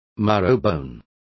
Complete with pronunciation of the translation of marrowbones.